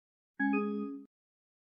Звуки подключения зарядки
Заряжаю телефон Huawei Honor